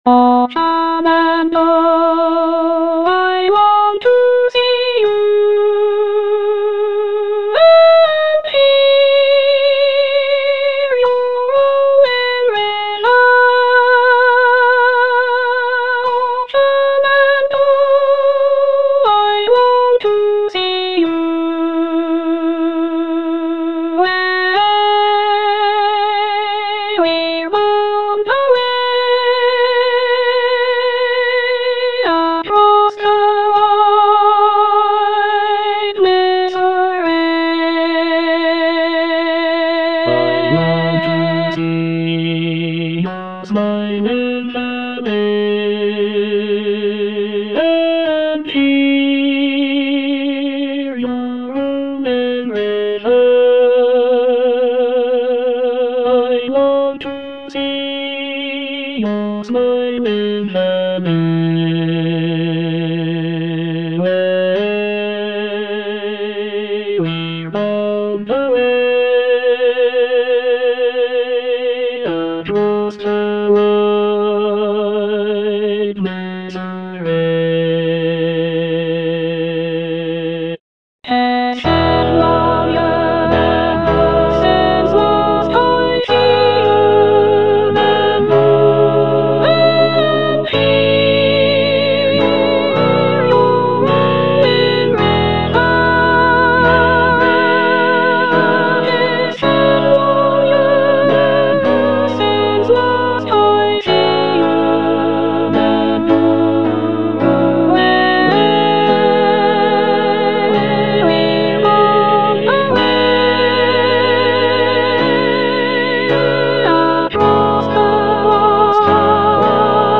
Soprano I (Emphasised voice and other voices)